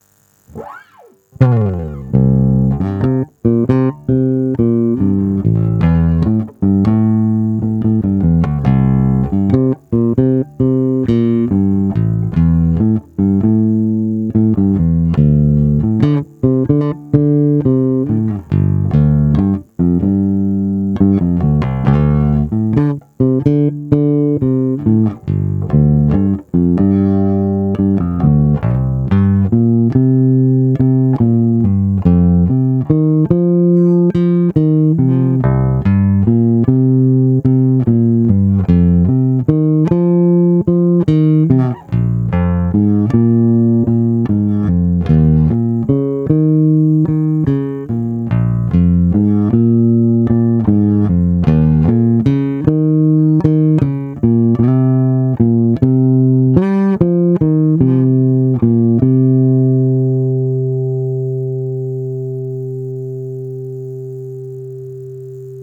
Nahrávky po úpravě se strunama Olympia:
Olympia nová tonovka na půl